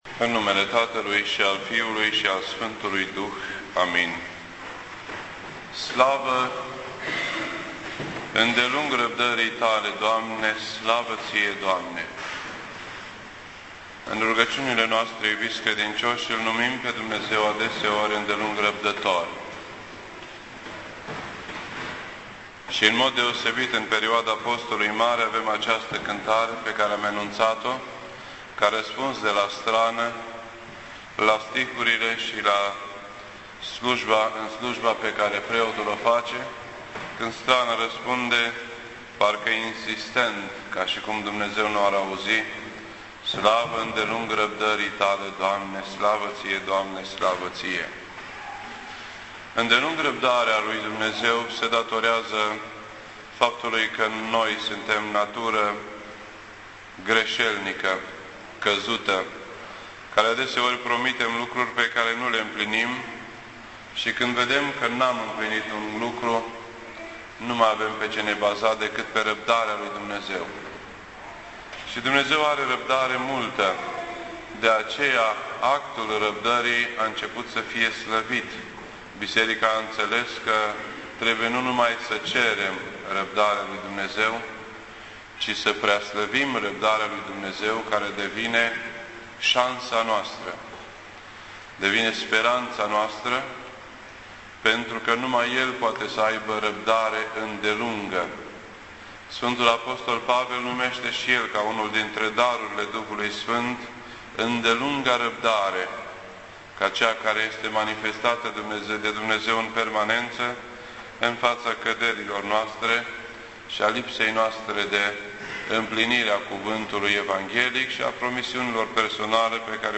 This entry was posted on Sunday, August 1st, 2010 at 9:04 PM and is filed under Predici ortodoxe in format audio.